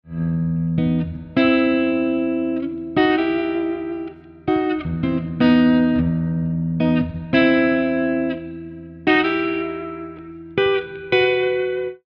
Having done this – I see some cool dyads ( 2 note voicings) on the B and G strings that I can use to spice up an E minor vamp.
Don’t be afraid to lay into the slides or add a little vibrato to make the notes sing a little more.
e-drone-diads.mp3